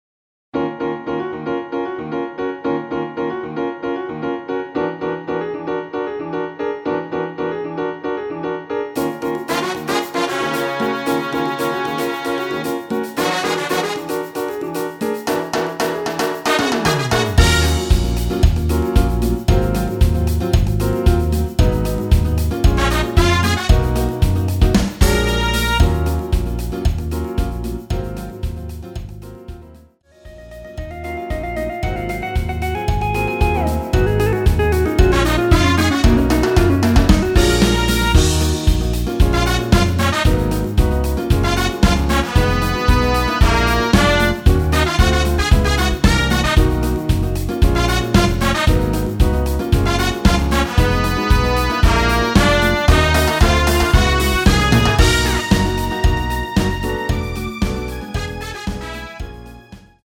Ebm
◈ 곡명 옆 (-1)은 반음 내림, (+1)은 반음 올림 입니다.
앞부분30초, 뒷부분30초씩 편집해서 올려 드리고 있습니다.